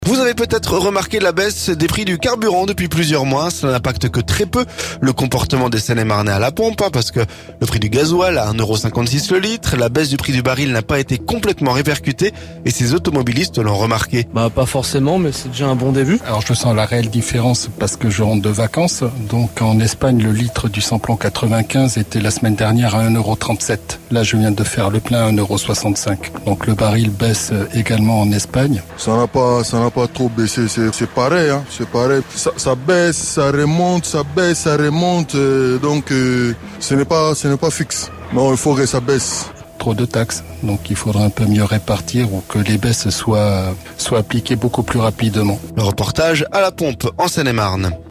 CARBURANT - La baisse des prix à la pompe pas assez marquée pour ces Seine-et-marnais rencontrés